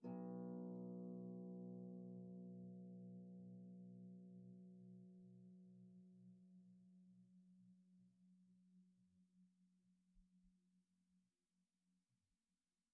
KSHarp_B1_mf.wav